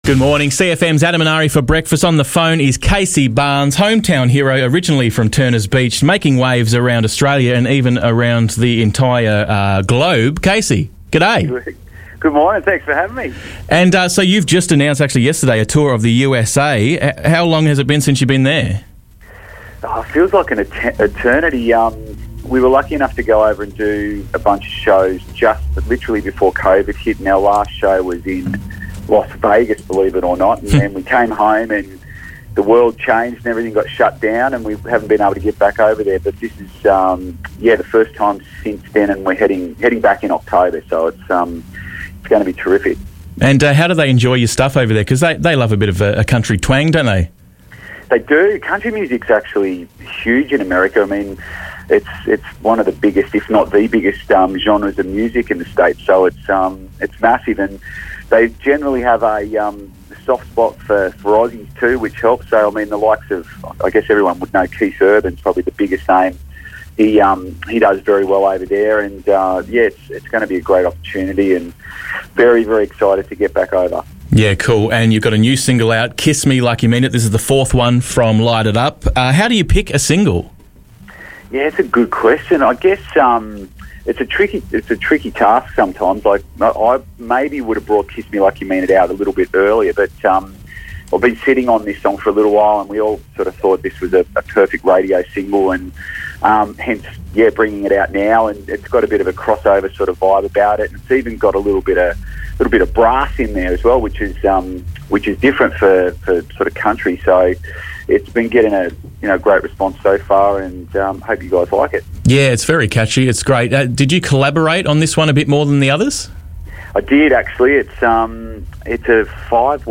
CLIP: Casey Barnes Interview